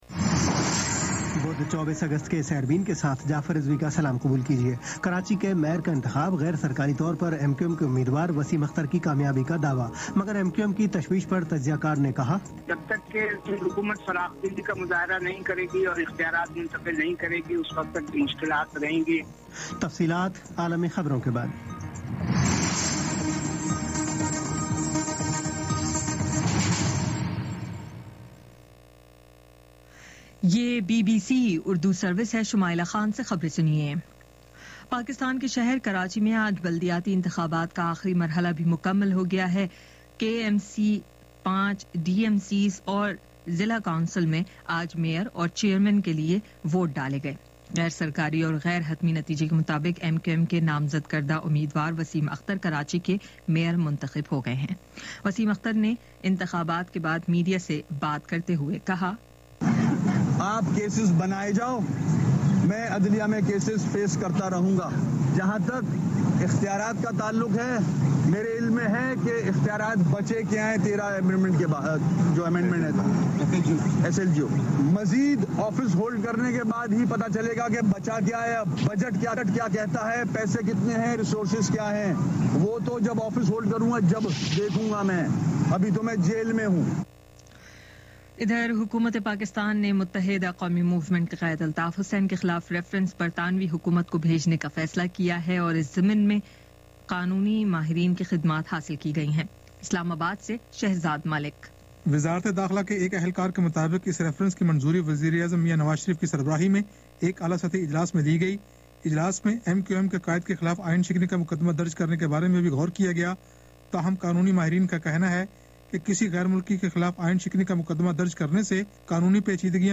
بدھ 24 اگست کا سیربین ریڈیو پروگرام